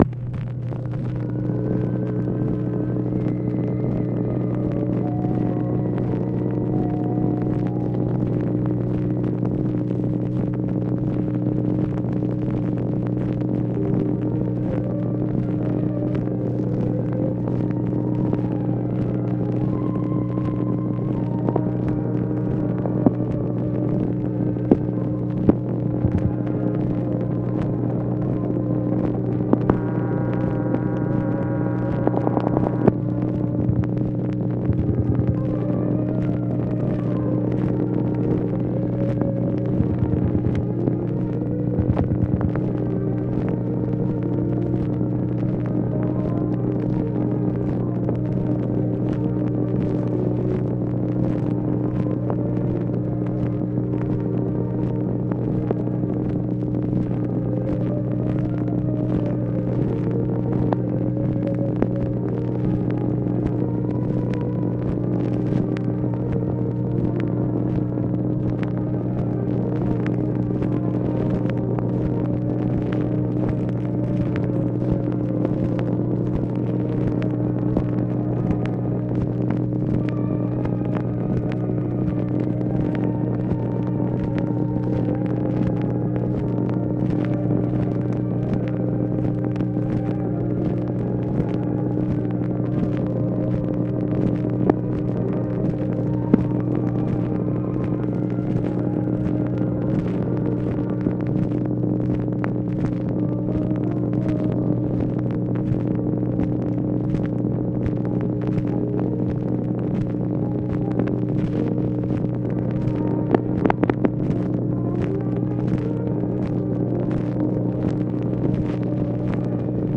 OFFICE NOISE, April 1, 1965
Secret White House Tapes | Lyndon B. Johnson Presidency